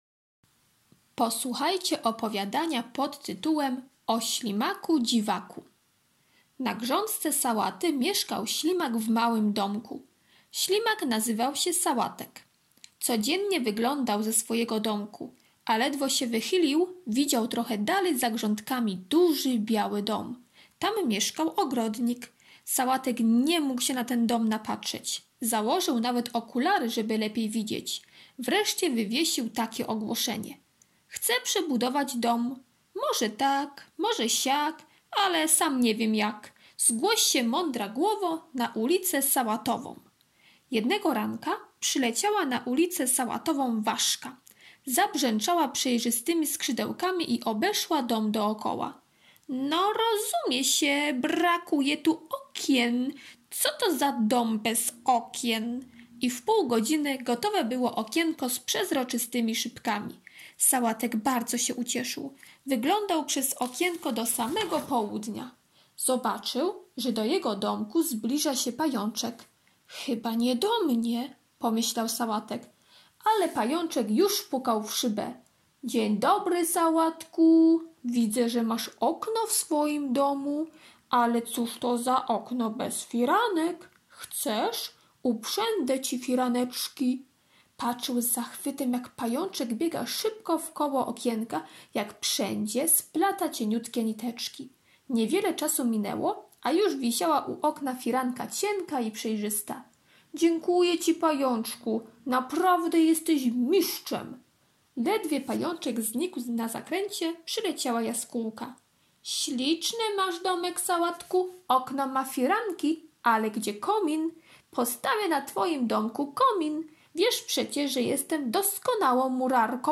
czwartek - opowiadanie "O ślimaku dziwaku" [6.47 MB] czwartek - propozycje pracy plastycznej (bez drukowania) [713.50 kB] czwartek - ćw. dla chętnych - pisanie litery Z, z [248.94 kB]